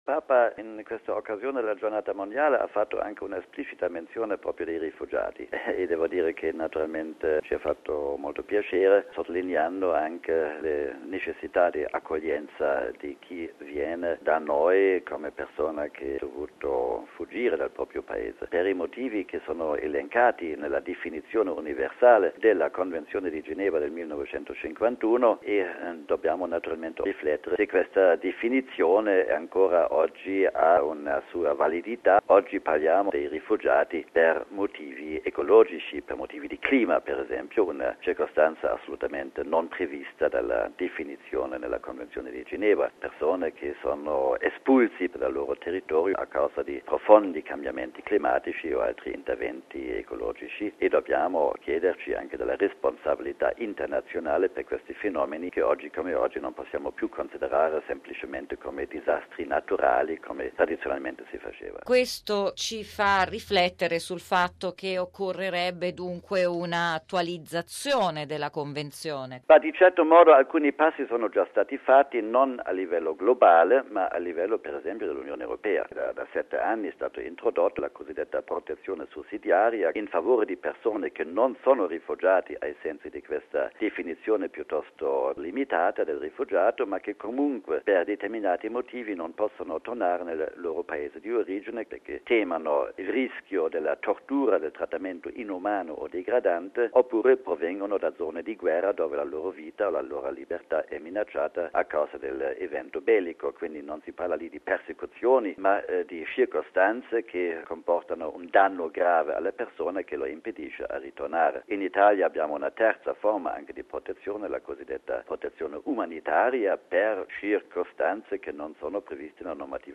Radiogiornale del 18/01/2012 - Radio Vaticana